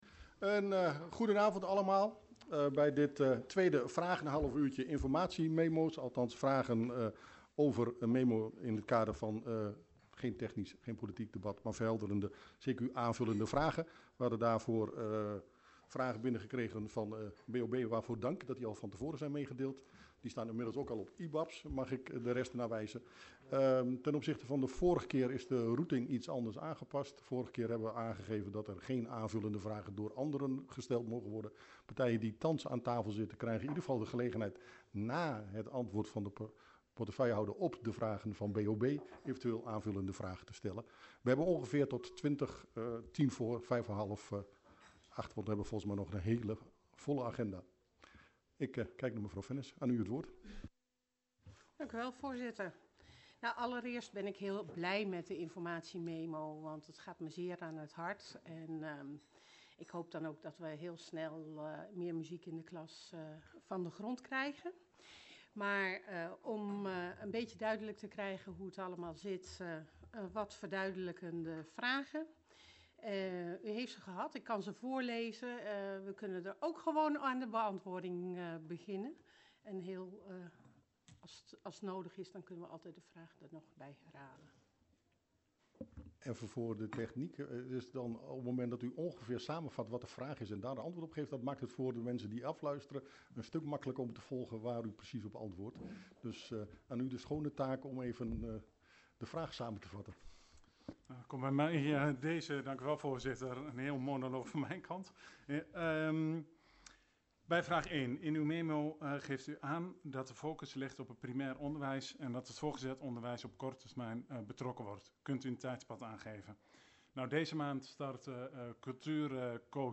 Dorpshuis De Schakel, Oosterhout